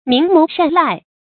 明眸善睞 注音： ㄇㄧㄥˊ ㄇㄡˊ ㄕㄢˋ ㄌㄞˋ 讀音讀法： 意思解釋： 形容女子的眼睛明亮而靈活。